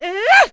Laudia-Vox_Attack2.wav